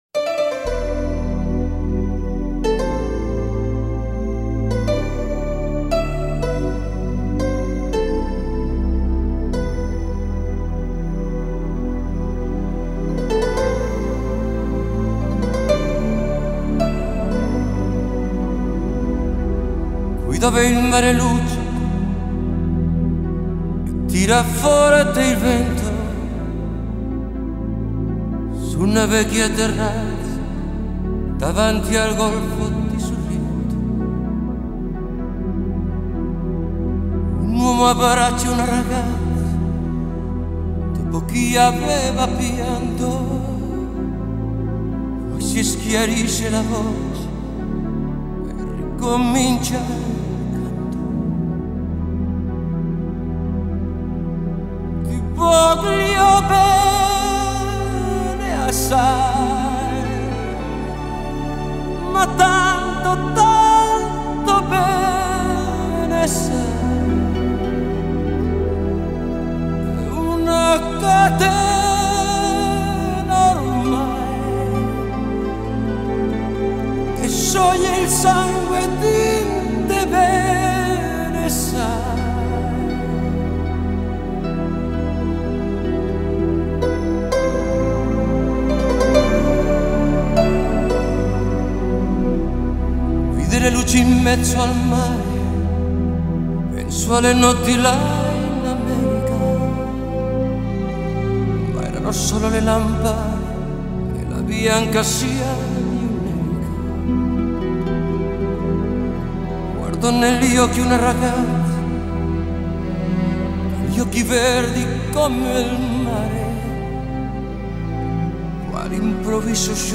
Drums
Keyboards
Synthesizer
Bass